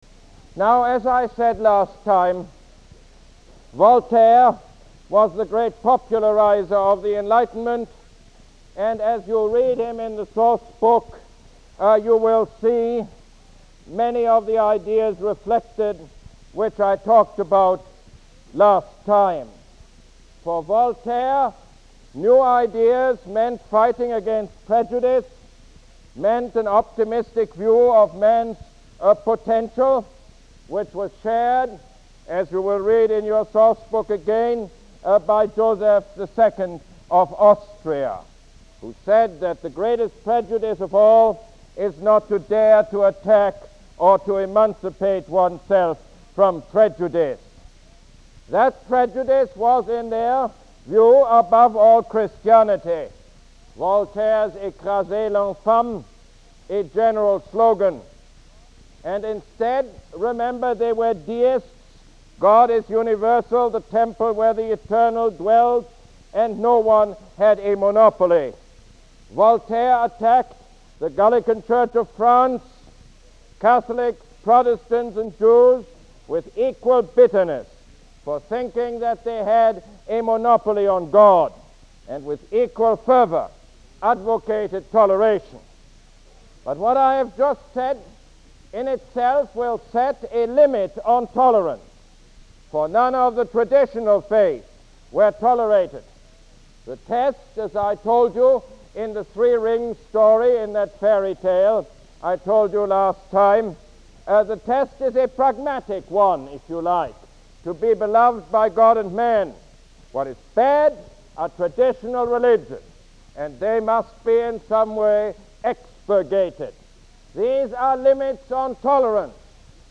Lecture #21 - The Enlightenment